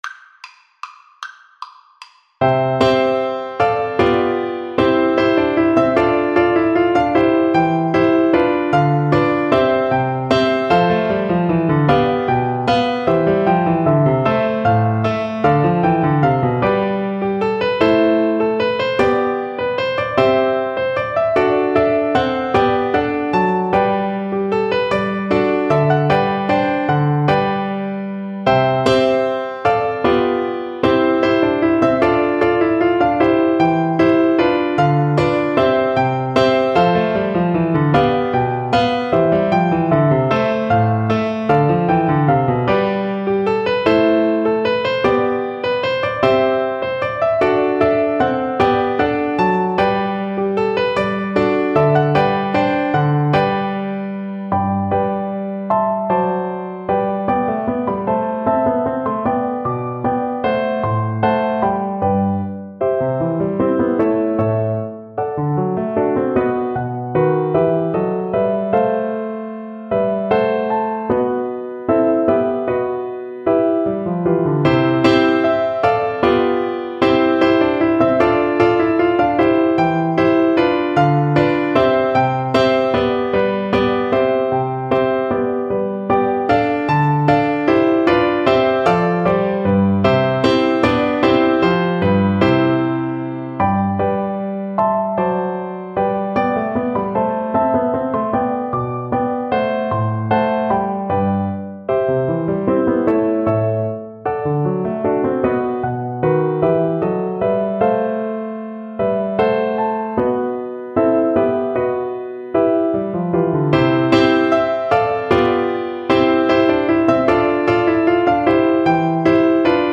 3/8 (View more 3/8 Music)
Classical (View more Classical Viola Music)